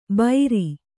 ♪ bairi